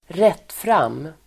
Uttal: [²r'et:fram:]